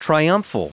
Prononciation du mot triumphal en anglais (fichier audio)
Prononciation du mot : triumphal